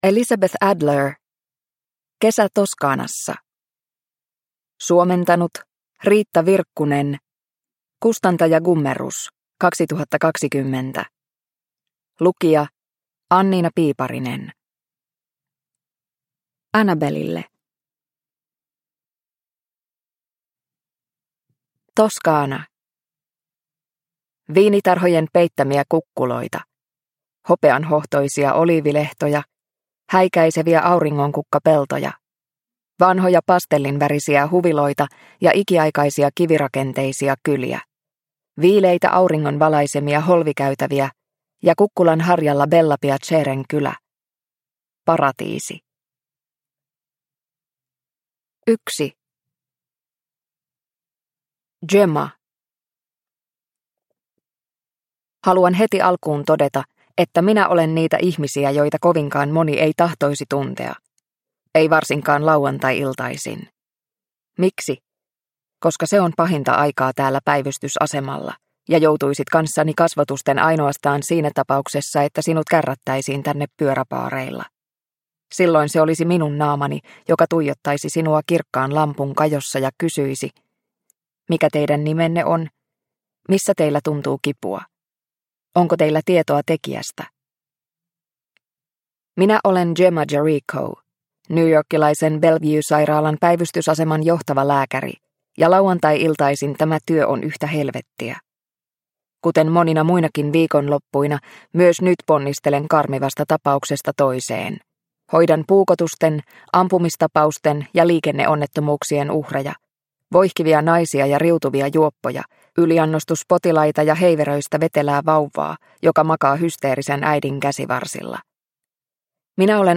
Kesä Toscanassa – Ljudbok – Laddas ner